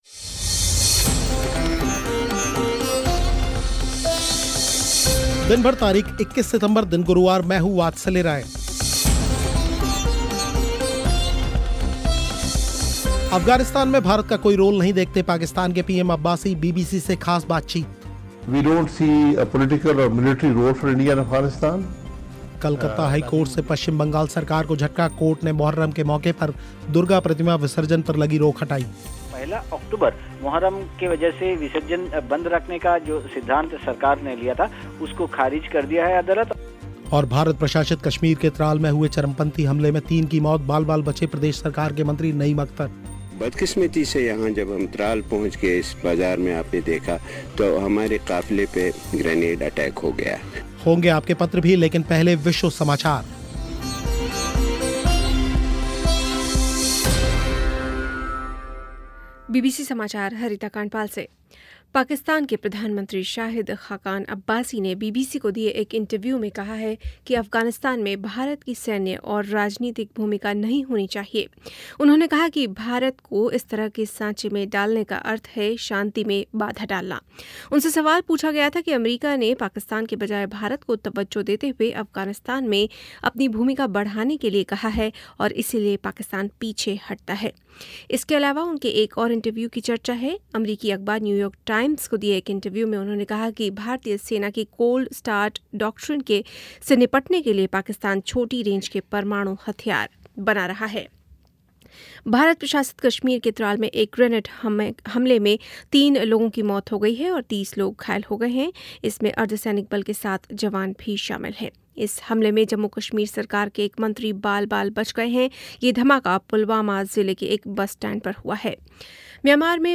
अफगानिस्तान में भारत का कोई रोल नहीं देखते पाकिस्तान के पीएम अब्बासी, बीबीसी से खास बातचीत